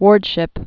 (wôrdshĭp)